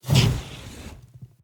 Spells
Firebuff 1.wav